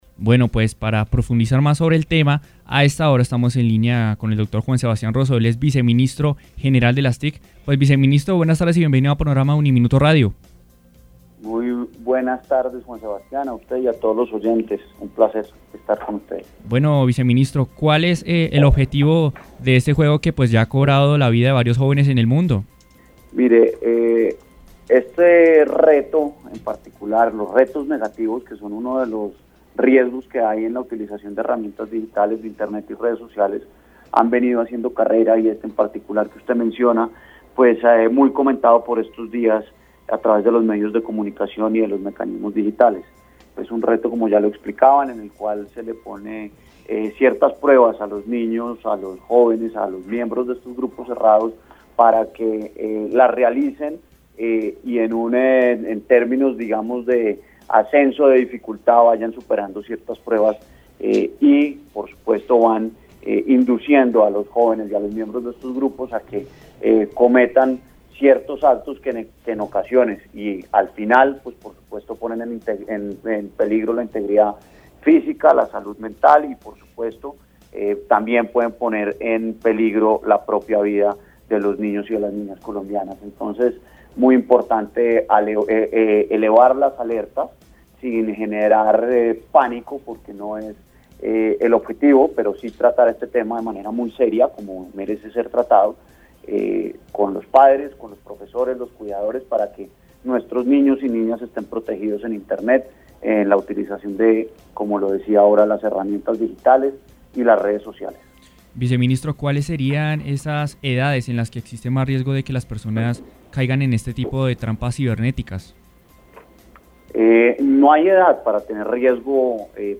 En diálogo con UNIMINUTO Radio estuvo Juan Sebastián Rozo, viceministro de las TIC, quien alertó a los padres de familia sobre este peligroso juego digital de retos que ya se está volviendo popular entre los jóvenes y niños colombianos , el denominado “La ballena azul” y que ya ha cobrado la vidas.